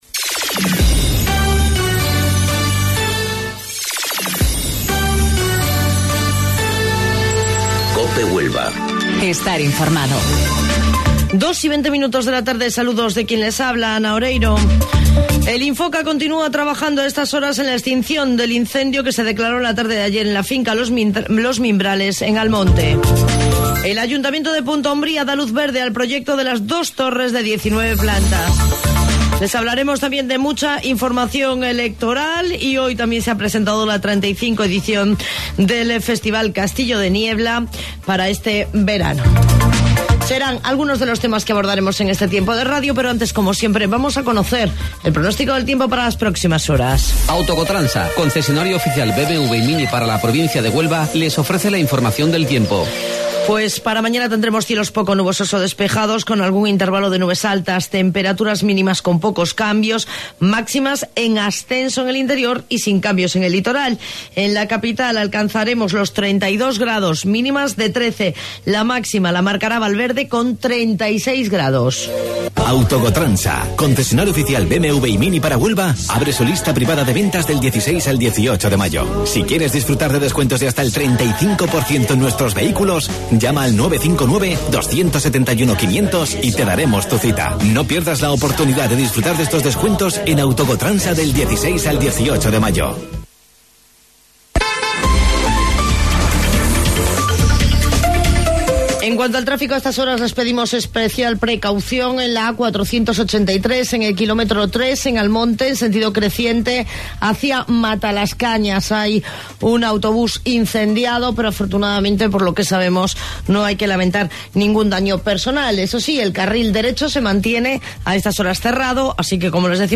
AUDIO: Informativo Local 14:20 del 14 de Mayo